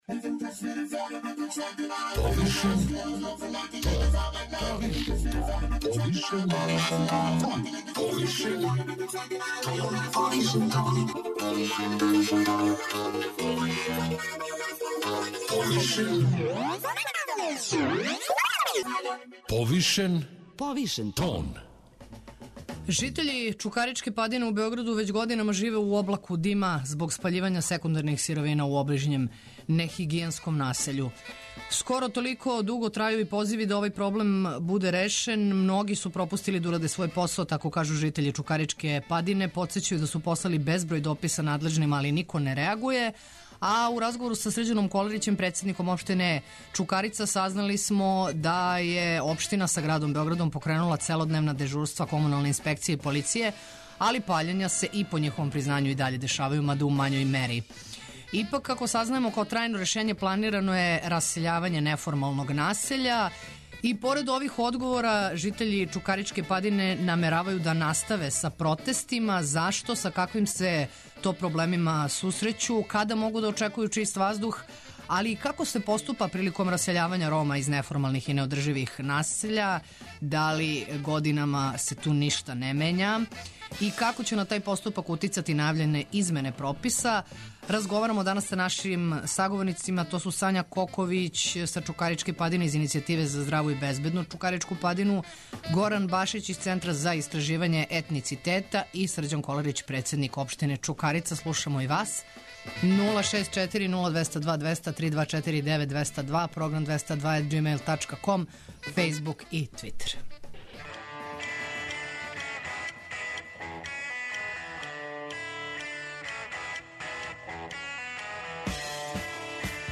Успели смо да разговарамо са Срђаном Коларићем , председником општине Чукарица који је за 202-ку рекао да је општина са градом Београдом покренула целодневна дежурства комуналне инспекције и полиције али паљења се и даље дешавају, мада у мањој мери.